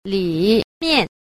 5. 裡面 – lǐmiàn – lý diện (bên trong)